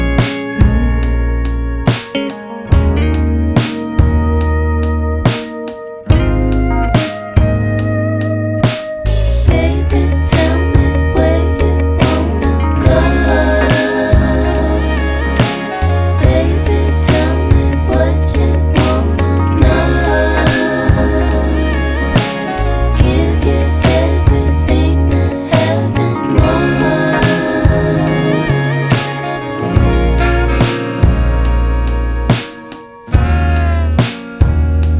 Жанр: Альтернатива / Кантри / Фолк-рок